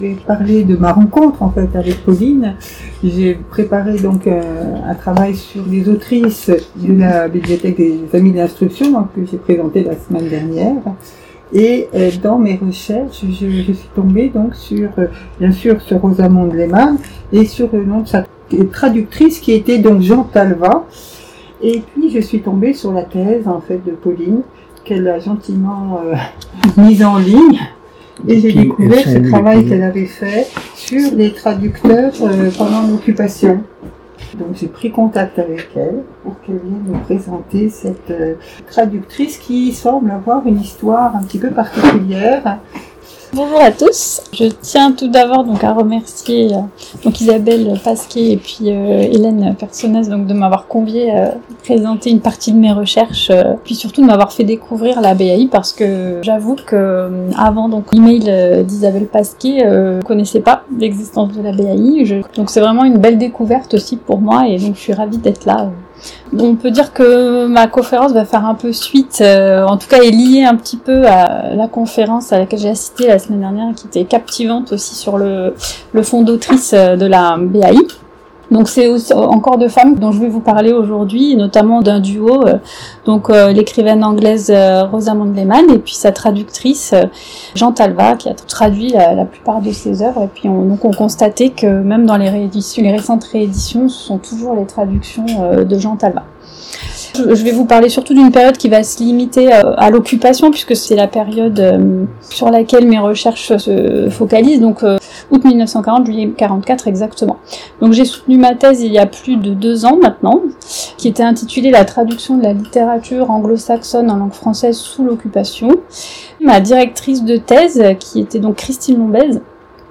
Conférence du 24 mai 2025